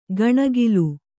♪ gaṇagilu